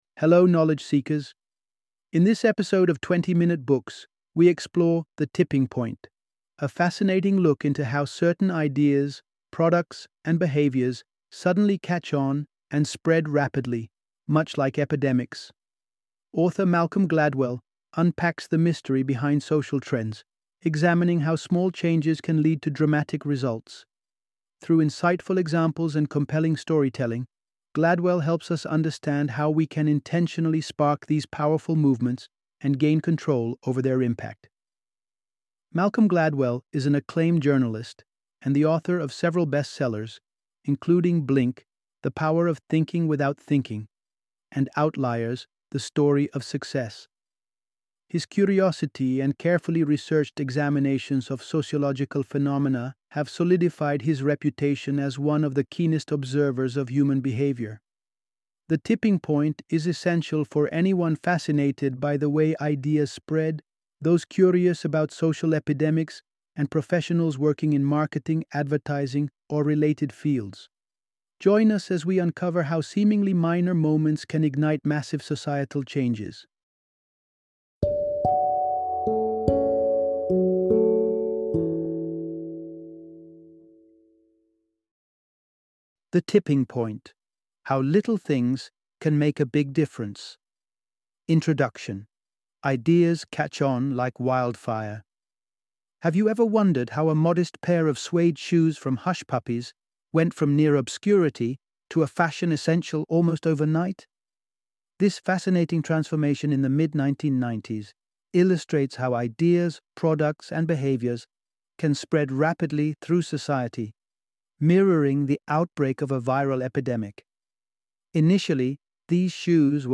The Tipping Point - Audiobook Summary